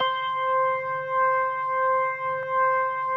B3LESLIE C 6.wav